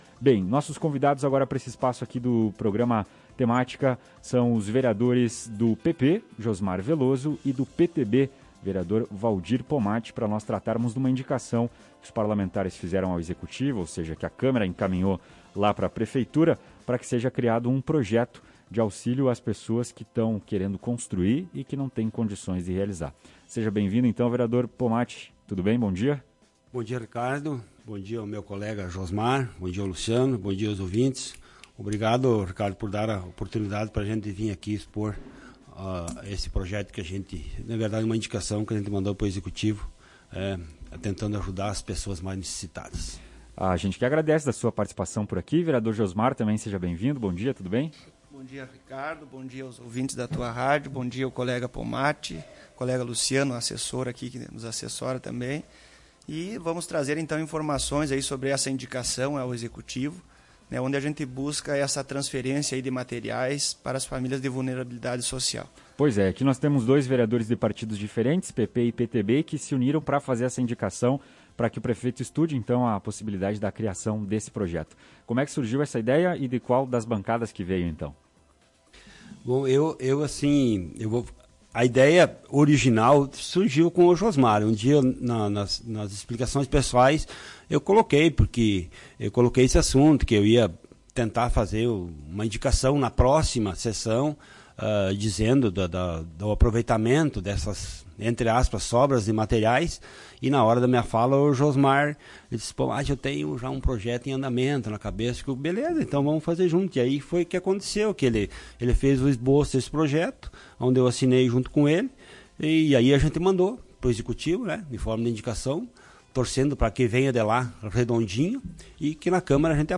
Ouça a entrevista com os parlamentares e saiba mais.